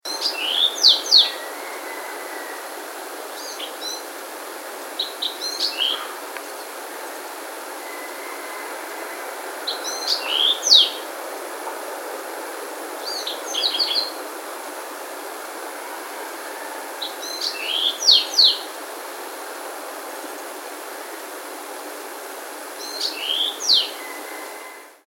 d. Song: variable, high opening notes, followed by lower burry notes, ending on a thin trill; sometimes suggests Song Sparrow (P).
Another rendering is swee swee cheeeeeeee (first and second notes high, last trilled) (P).
"Spring song" consists of a few weak introductory notes followed by a descending trill (L).
82 Bewick's Wren song 2 types.mp3